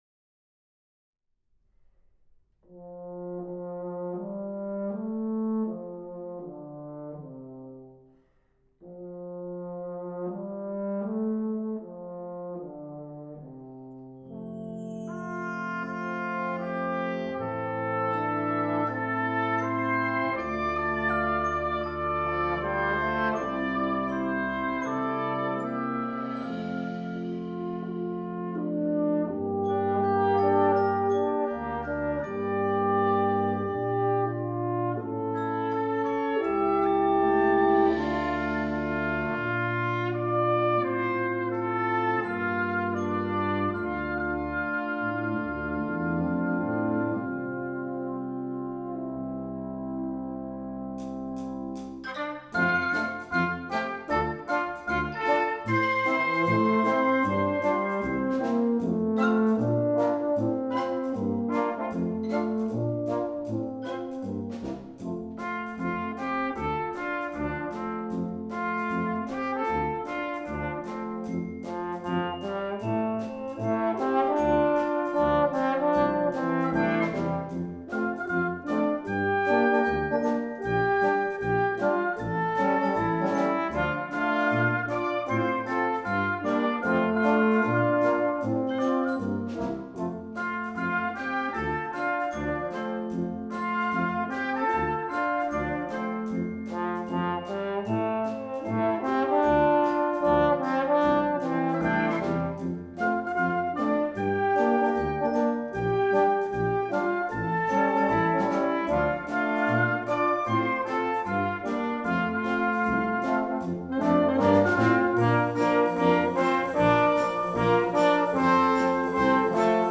Partitions pour ensemble flexible, 6-voix + percussion.